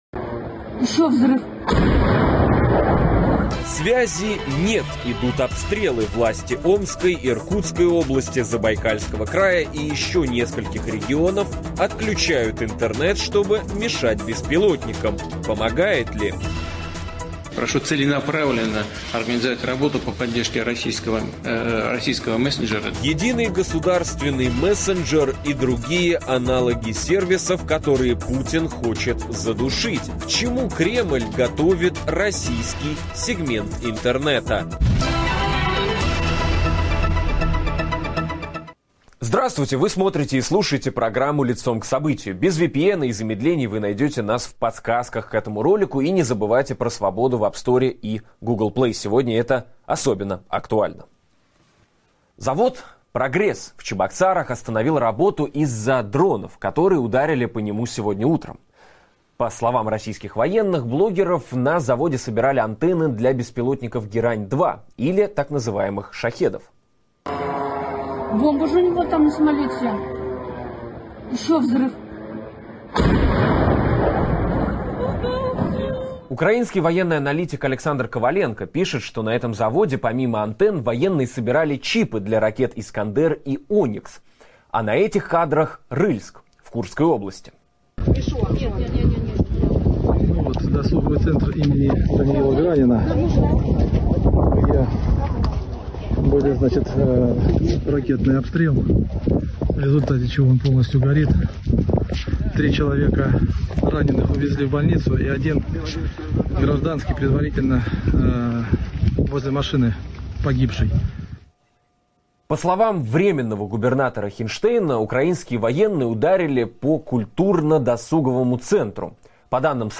говорим с экспертом в области интернет-безопасности